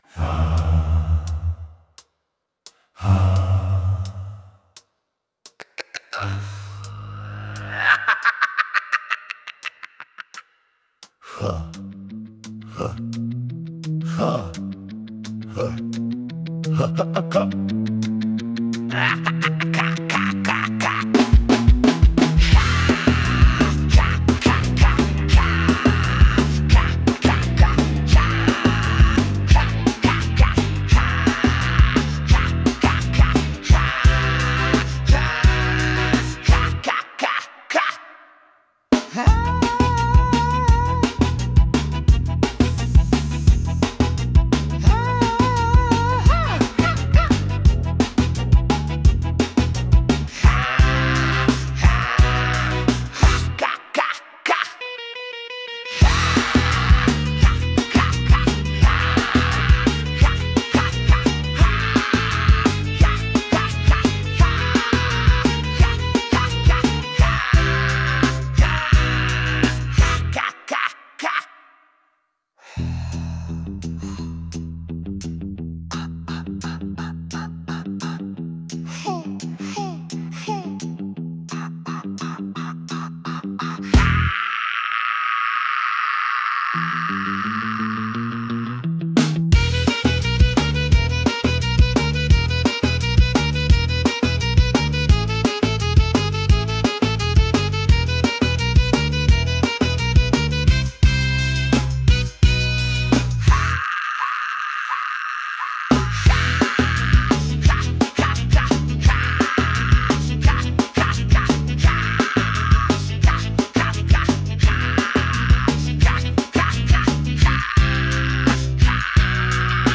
• Исполняет: Поставторcкий арт
(Вступление: Distorted гитара, быстрая барабанная дробь)